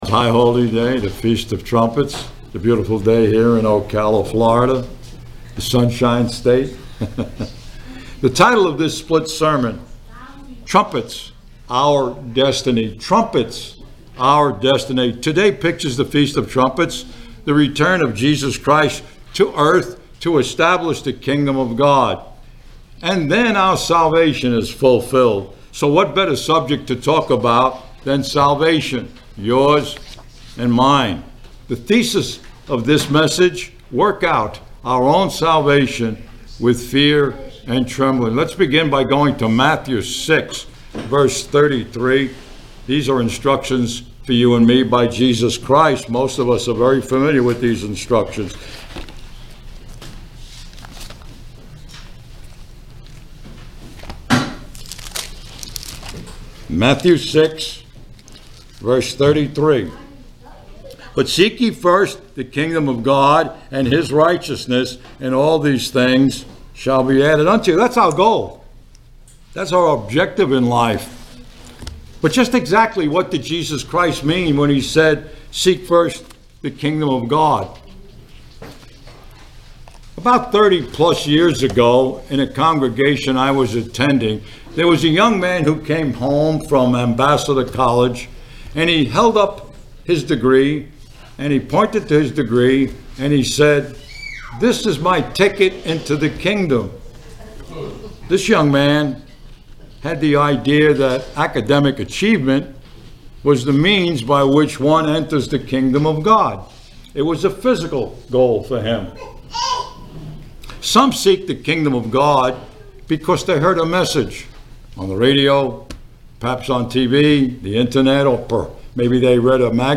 Sermons
Given in Ocala, FL